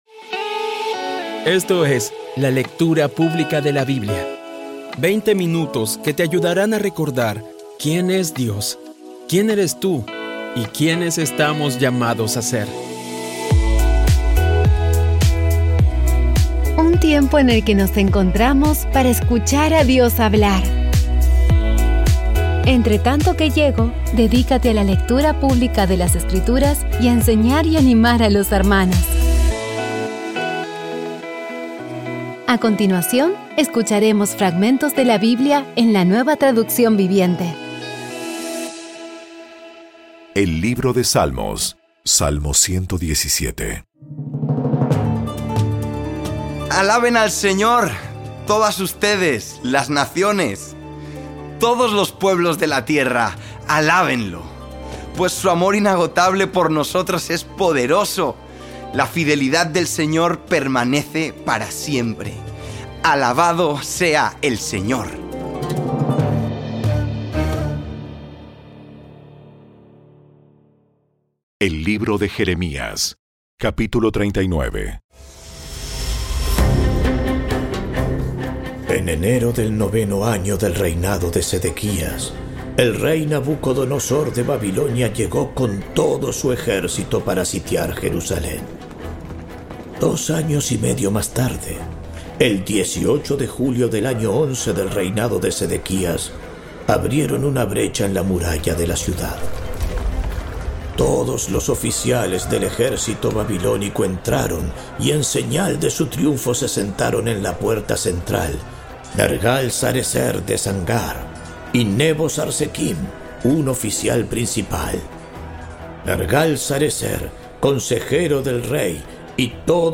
Audio Biblia Dramatizada Episodio 291
Poco a poco y con las maravillosas voces actuadas de los protagonistas vas degustando las palabras de esa guía que Dios nos dio.